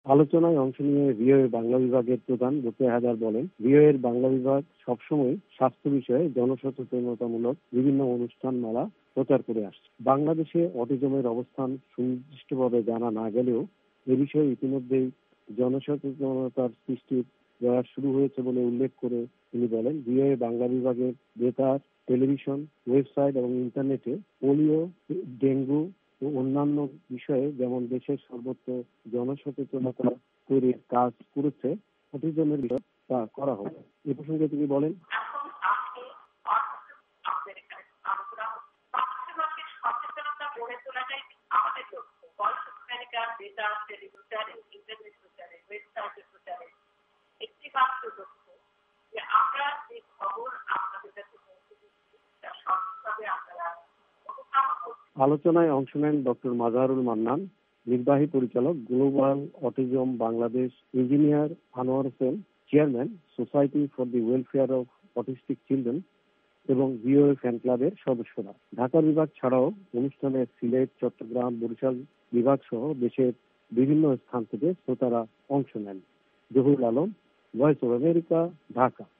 ঢাকা বিভাগ ভিওএ ফ্যান ক্লাবের আয়োজনে বৃহস্পতিবার ঢাকায় জাতীয় প্রেস ক্লাবে এক শ্রোতা সম্মেলন অনুষ্ঠিত হয়। সম্মেলনে অটিস্টিক শিশু ও তাদের ভবিষ্যৎ নিয়ে আলোচনা হয়।